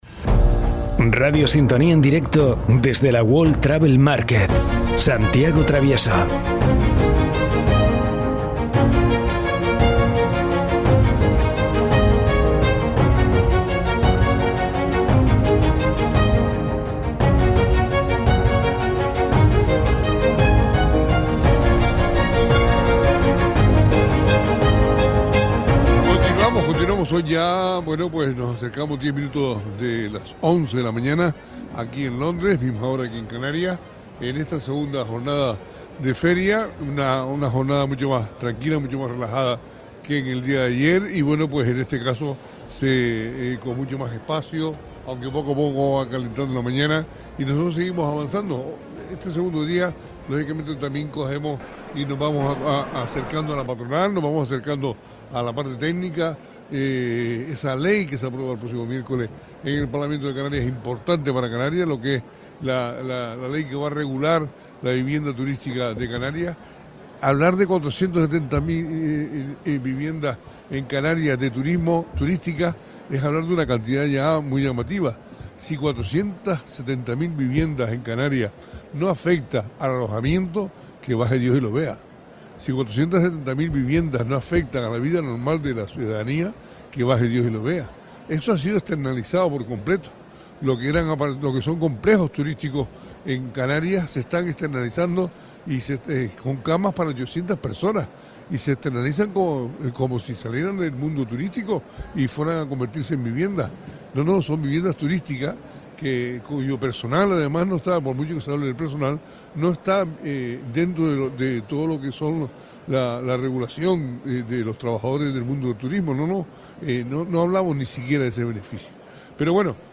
David Fajardo, concejal de Turismo y Medioambiente de La Oliva, y el alcalde Isaí Blanco fueron entrevistados en el especial de Radio Sintonía desde la World Travel Market de Londres.